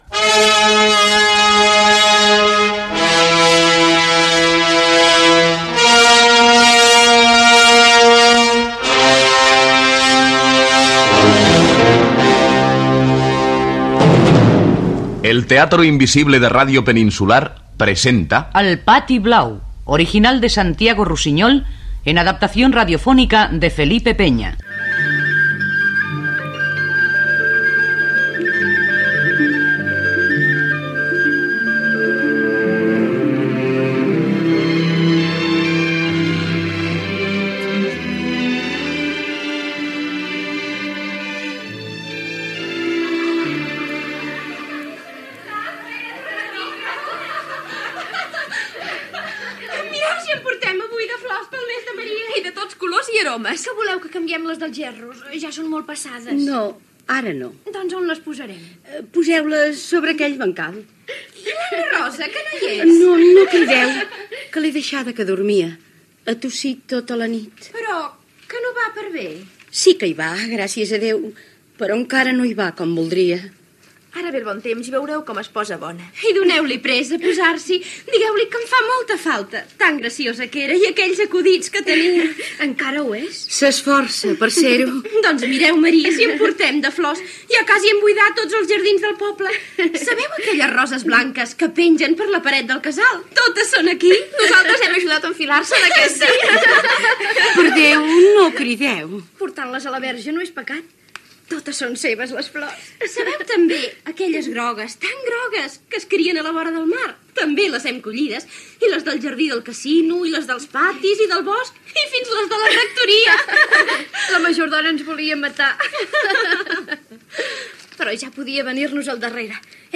Adaptació radiofònica de l'obra "El pati blau" de Santiago Rusiñol.
Ficció